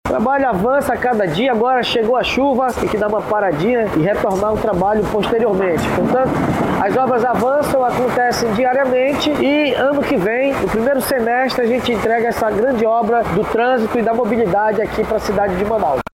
Durante visita ao canteiro de obras, o chefe do Executivo Municipal explicou que os trabalhos vão passar por interrupções por conta do período de chuvas.